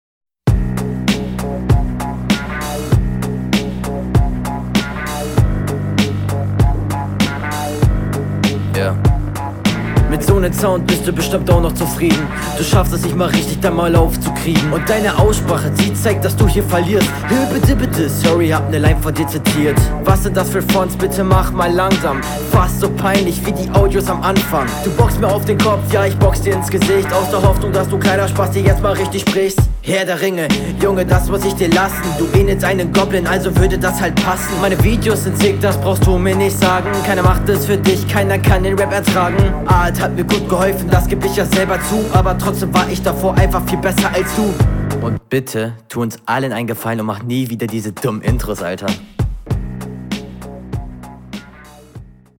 Flow: Jo ist solide und das ist viel besser als der gegner allein schon weil …
Flow: Ist ziemlich basic kann man sich geben trifft den Takt mit mehr druck und …